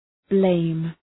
Προφορά
{bleım}